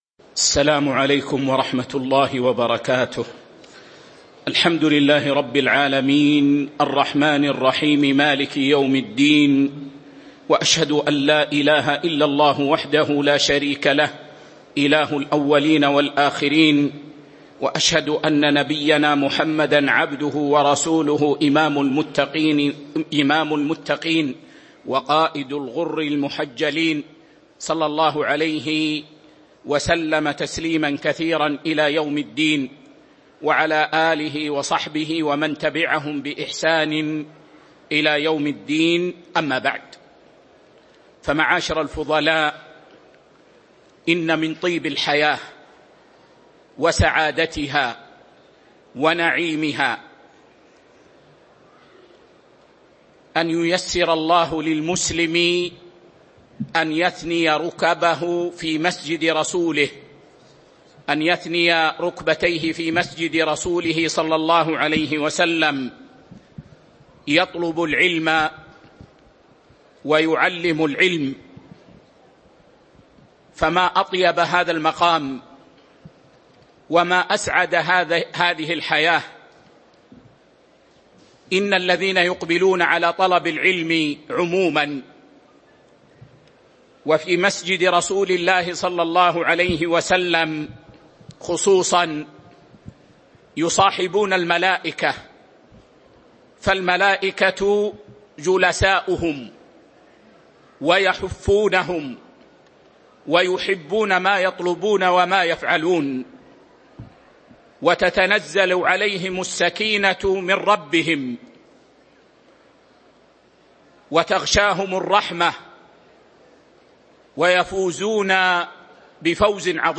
شرح دليل الطالب لنيل المطالب الدرس 404